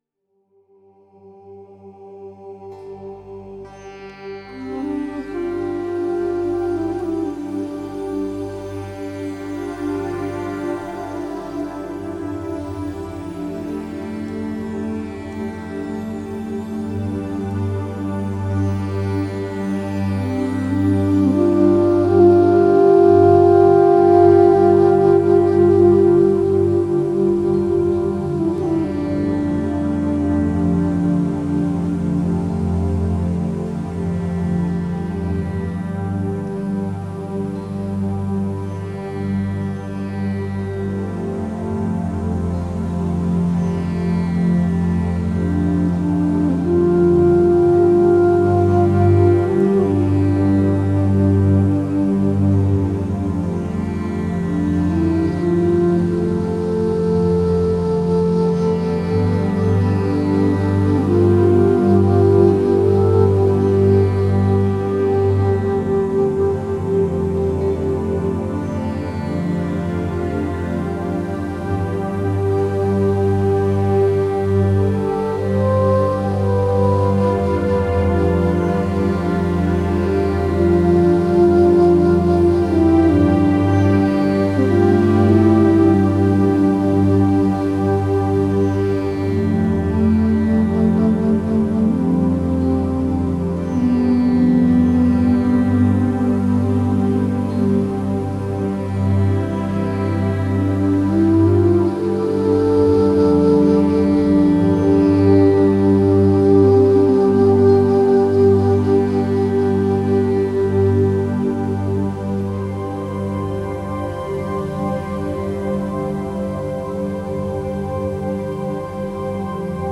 Нью эйдж Медитативная музыка New age